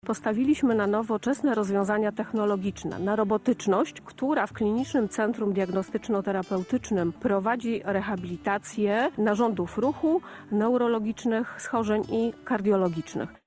„Czarne złoto”, czyli lecznicza borowiny, podobno ma właściwości odmładzające. O tym i o walorach przyrodniczych, turystyczny i gastronomicznych tego miejsca oraz Południoworoztoczańskiego Parku Krajobrazowego rozmawialiśmy podczas wizyty Radia Biwak w uzdrowisku.